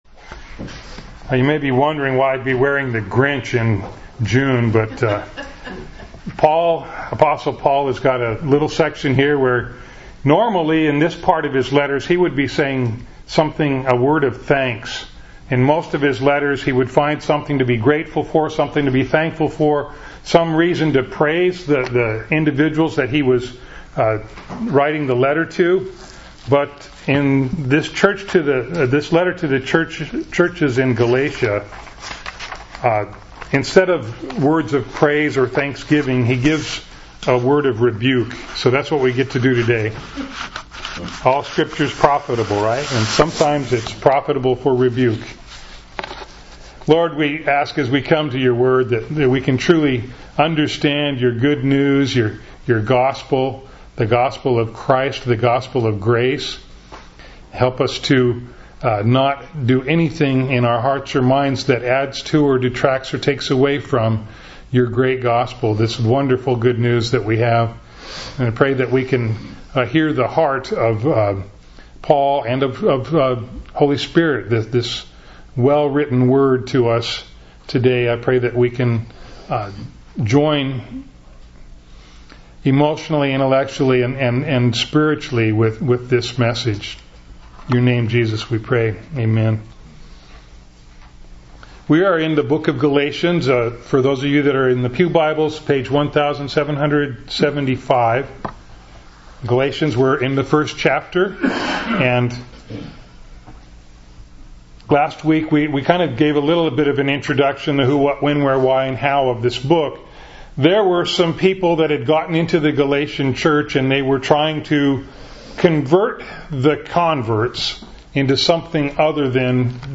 Bible Text: Galatians 1:6-10 | Preacher